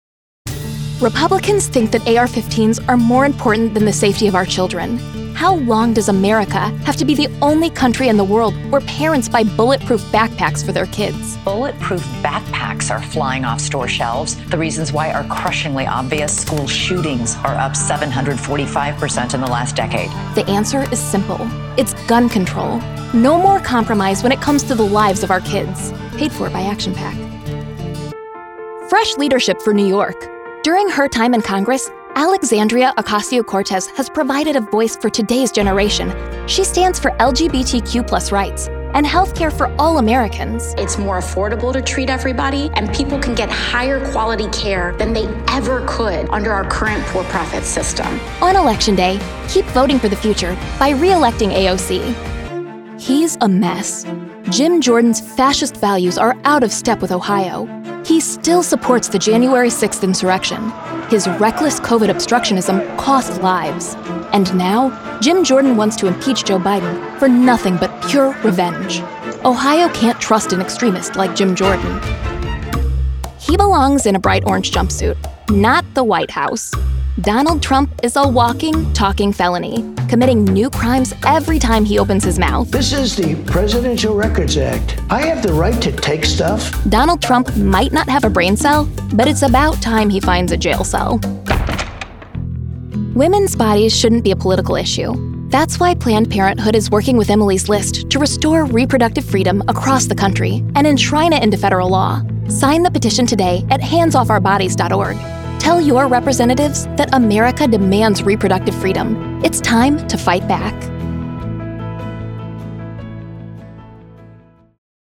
Voiceover Artist,
Sex: Female
Accents: US Midwest, US General American
Sennheiser MKH 416 microphone, Studiobricks One Plus VO Edition, Source Connect